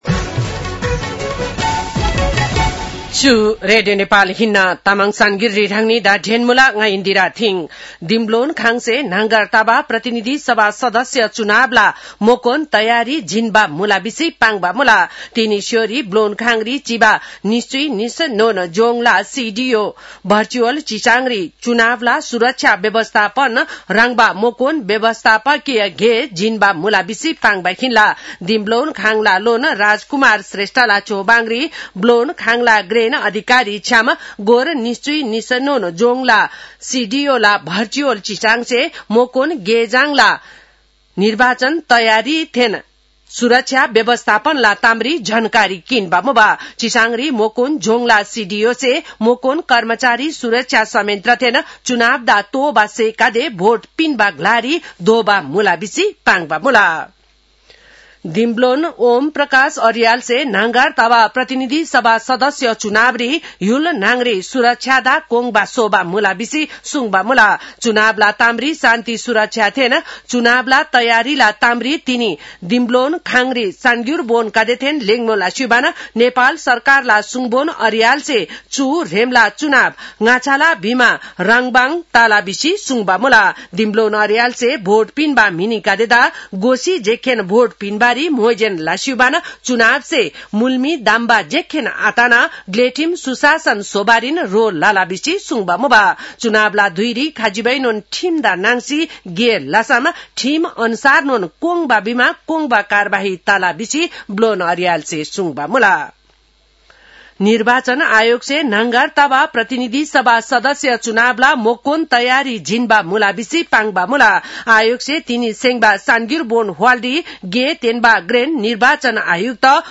तामाङ भाषाको समाचार : २० फागुन , २०८२
Tamang-news-5.5-pm.mp3